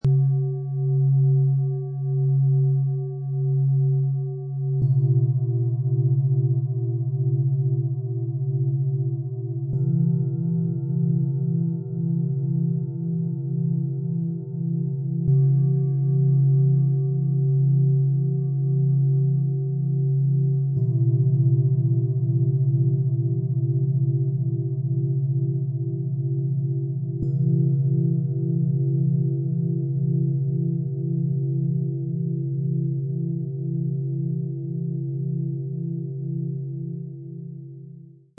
Inneren Rhythmus stärken, Ruhe finden und Herzräume weiten, kleineres Klangmassage - Set aus 3 Planetenschalen, Ø 18,3 -21,9 cm, 3,05 kg
Ein besonders weicher Ton, der emotionale Öffnung begleitet.
Der integrierte Sound-Player - Jetzt reinhören lässt den Originalklang dieser Schalen hörbar werden.
Tiefster Ton: Biorhythmus Körper, Biorhythmus Geist
Bengalen-Schale, glänzend
Mittlerer Ton: Mond
Höchster Ton: Hopi-Herzton, Mond
MaterialBronze